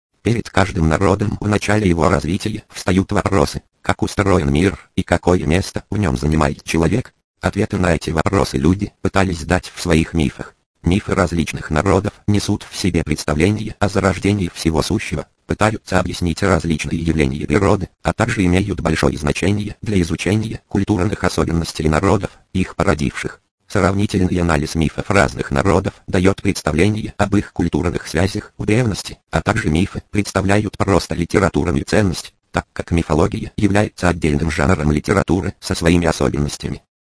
Elan Tempo Multimedia - один из лучших русскоязычных синтезаторов для чтения книг и их записи в аудиофайл. Использует знаменитый мужской голос "Nicolai 16KHZ". Работает на базе как SAPI4, так и SAPI5/SAPI5.1. Обладает выразительным голосом с возможностями интонационного чтения, интонация определяется автоматически: на основе уникальной системы анализа текста. Однако он не лишён некоторых минусов, например, его речь обладает лёгкой картавостью.